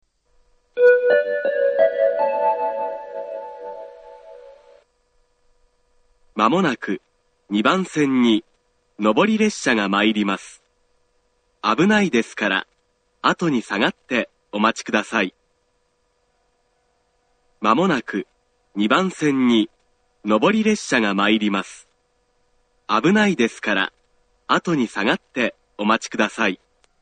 ２番線上り接近放送
tokai-2bannsenn-nobori-sekkinn1.mp3